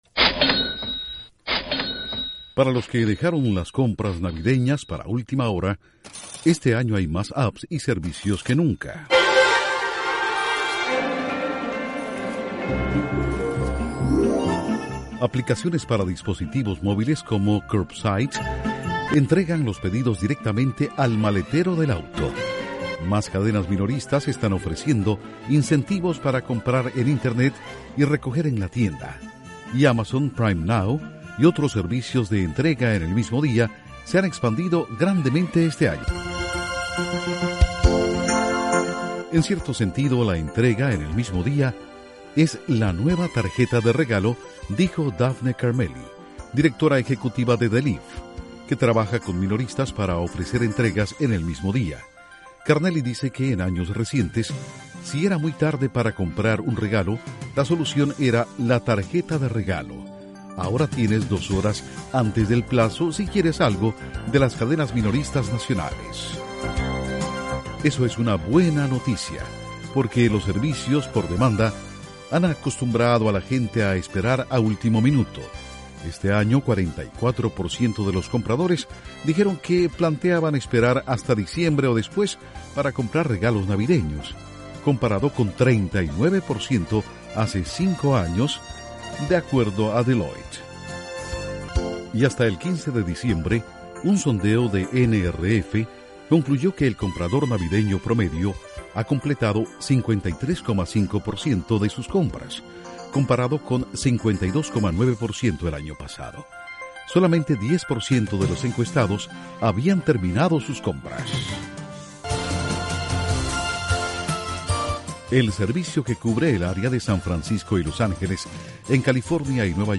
Internet agiliza y facilita compras navideñas de última hora como nunca antes. Informa desde la Voz de América